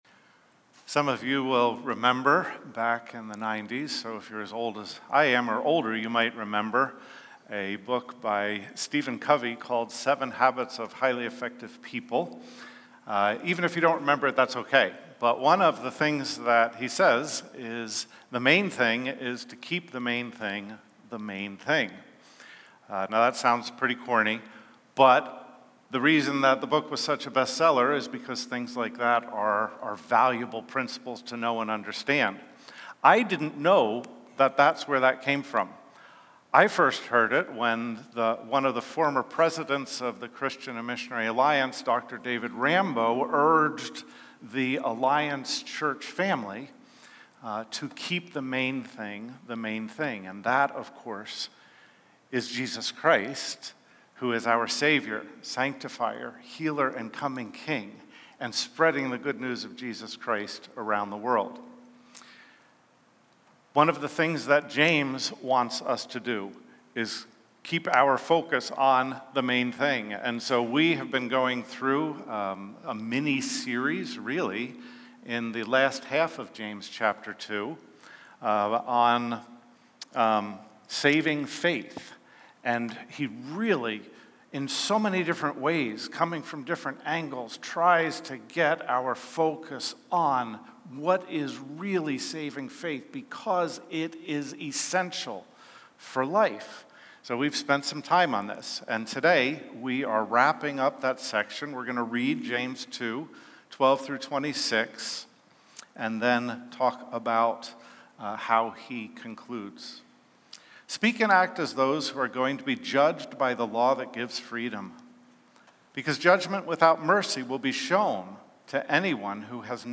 James 2:26 audio_file Sermon Audio notes Transcript podcasts Podcast description Notes Share